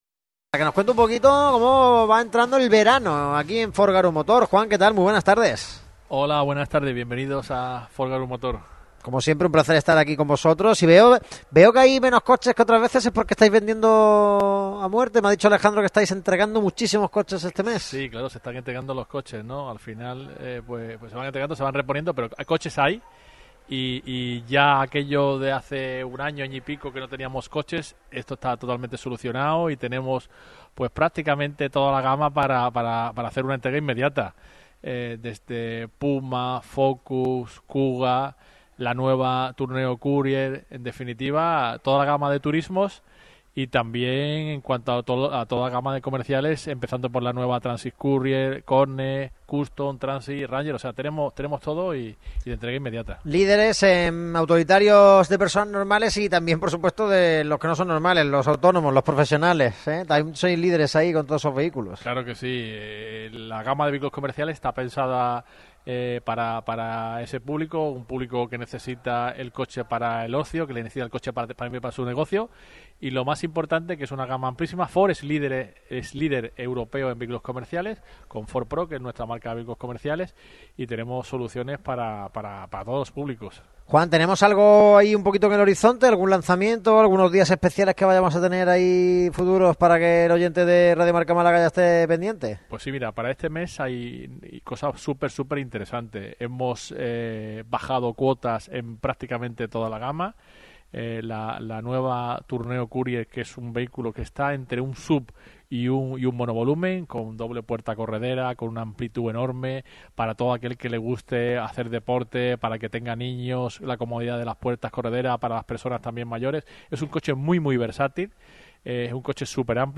Radio Marca Málaga vuelve una vez más a Ford Garum Motor, en la avenida Luis XXIII, concesionario oficial de la marca estadounidense en la capital costasoleña, de los miembros por excelencia en la familia de la radio del deporte.